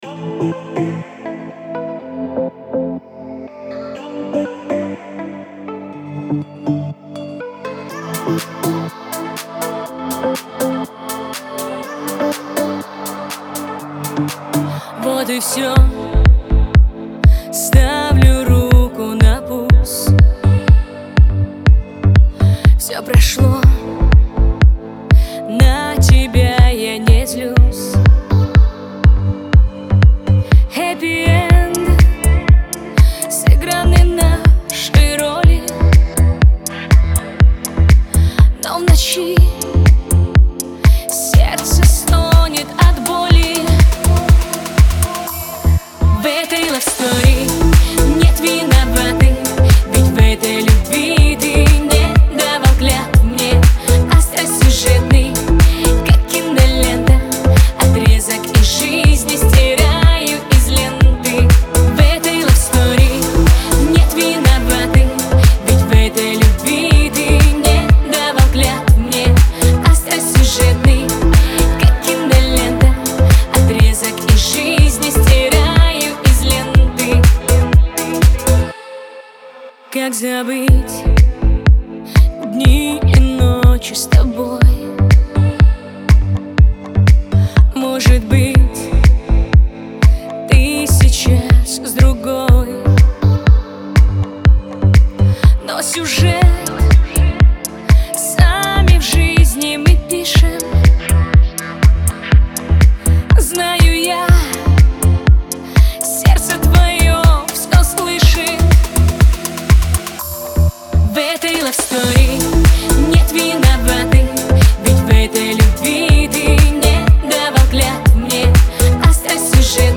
романтичная поп-песня